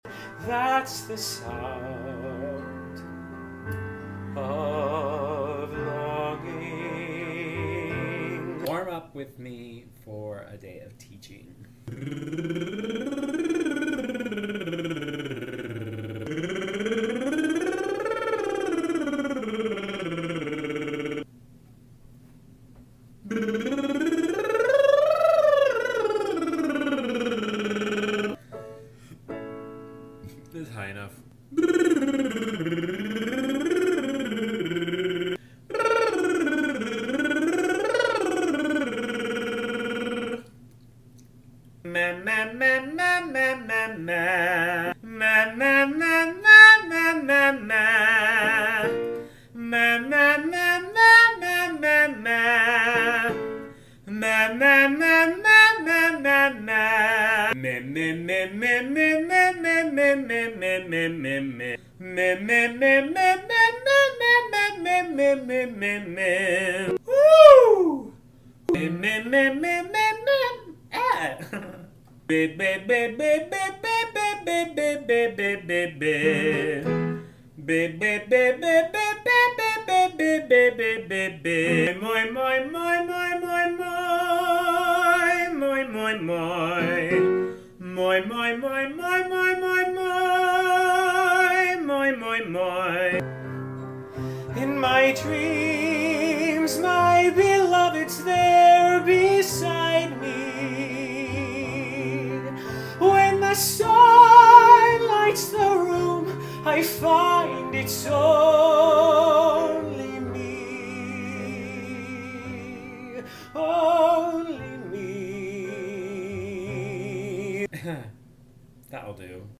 I tell my students all the time that you’ve gotta be willing to make ugly sounds in order to make pretty ones. What’s your ugliest warm up exercise?